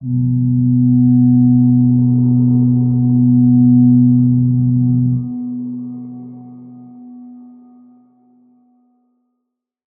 G_Crystal-B3-f.wav